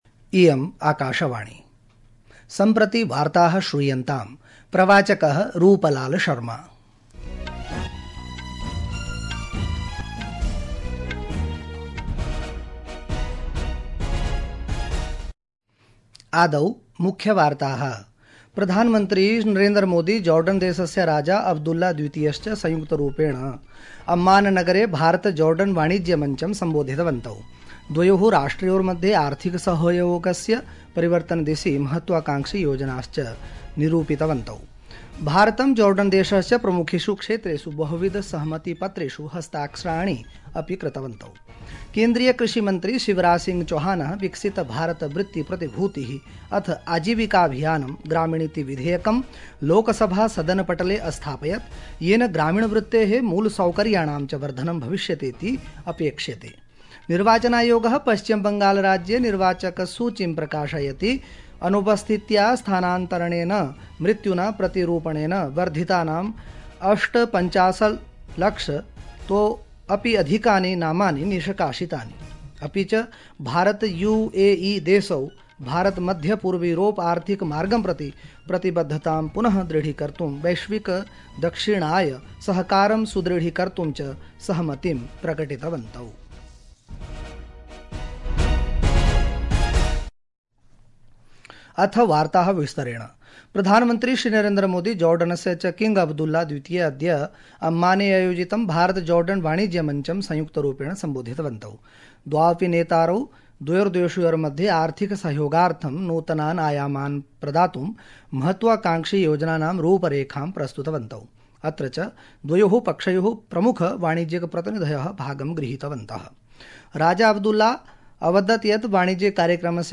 Sanskrit-News-1820.mp3